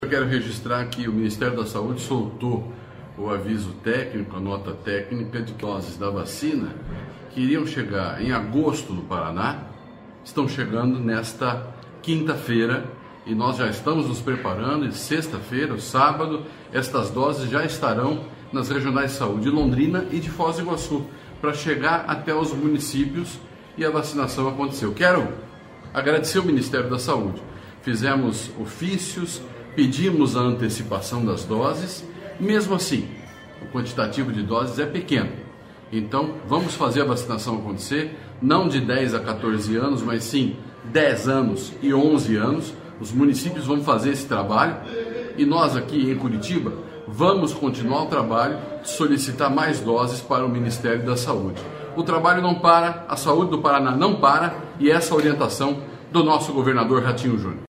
Sonora do secretário Estadual da Saúde, Beto Preto, sobre a chegada de 35 mil doses de vacinas contra a dengue ao Paraná